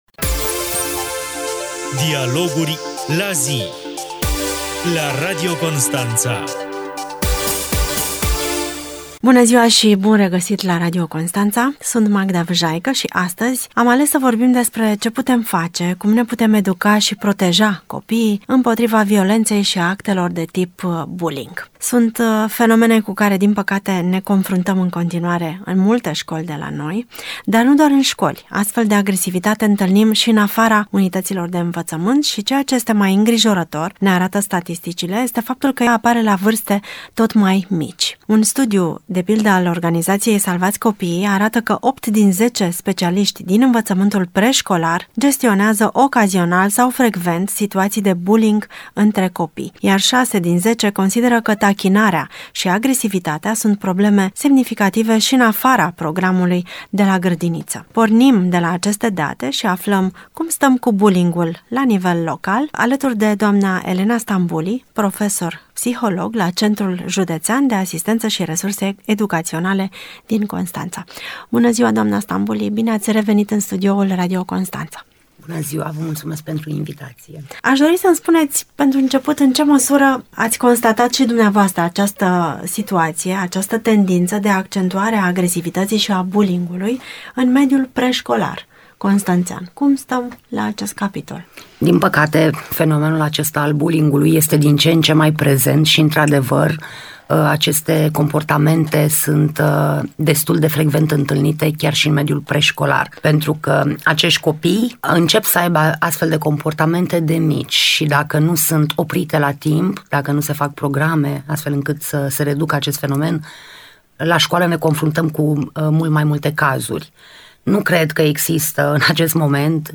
Emisiunea ”Dialoguri la zi